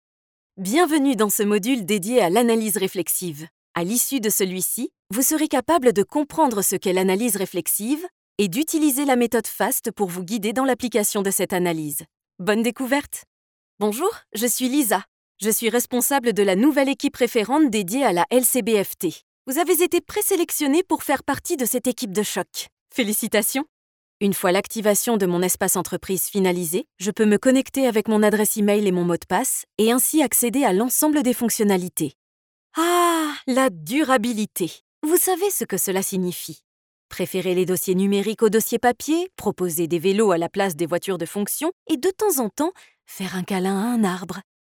Natural, Distinctive, Soft, Commercial, Friendly
E-learning